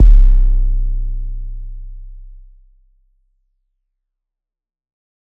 • 50 high-quality, hard-hitting 808 drum samples.
• A variety of tones from clean subs to gritty distortion.